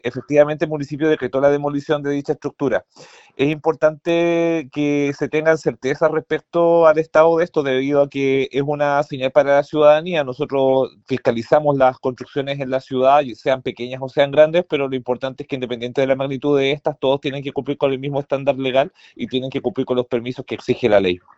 El presidente de la Comisión de Vivienda y Urbanismo del Concejo Municipal, el concejal del Frente Amplio, Cristóbal Rosas, afirmó que dicha estructura ha generado preocupación y que el dueño de la misma levantó tres solicitudes para construir.